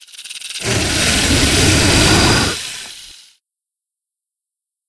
c_wserpent_dead.wav